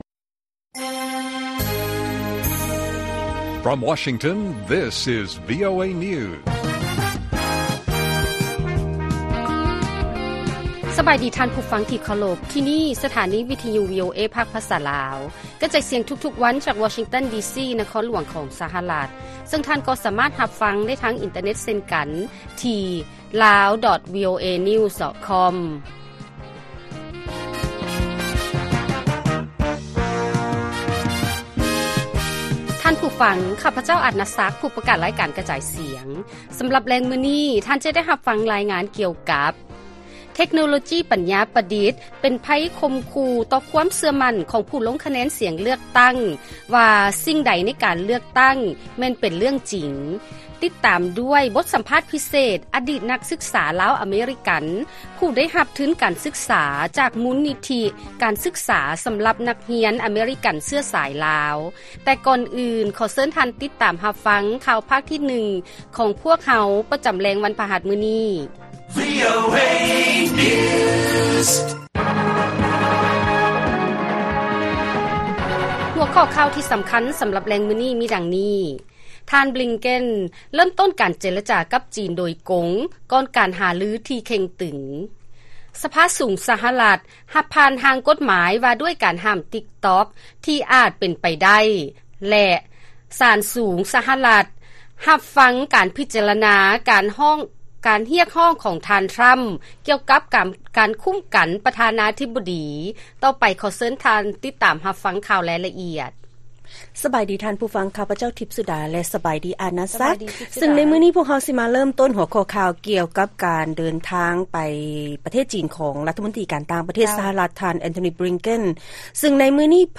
ລາຍການກະຈາຍສຽງຂອງວີໂອເອລາວ: ທ່ານ ບລິງເກັນ ເລີ້ມຕົ້ນການເຈລະຈາກັບ ຈີນ ໂດຍກົງ ກ່ອນການຫາລືທີ່ເຄັ່ງຕຶງ